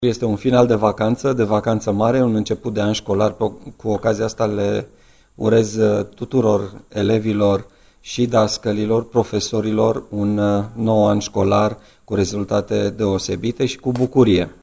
Din nou, Marian Rasaliu: